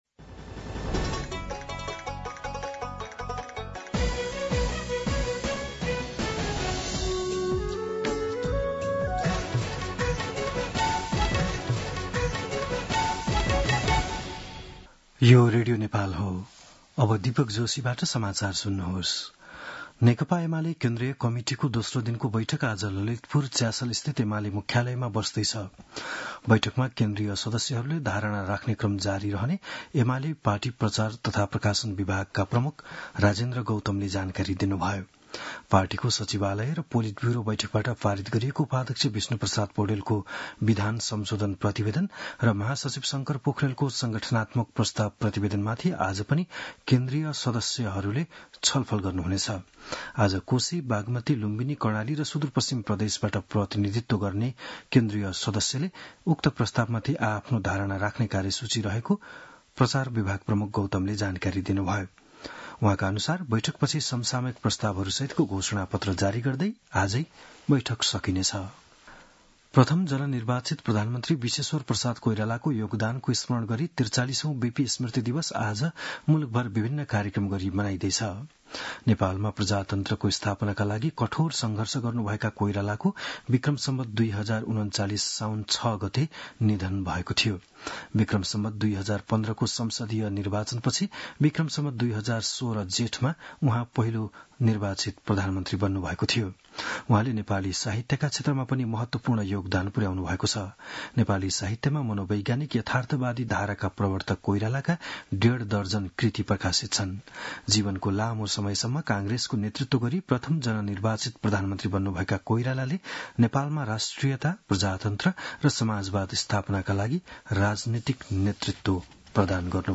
बिहान ११ बजेको नेपाली समाचार : ६ साउन , २०८२
11am-News-06.mp3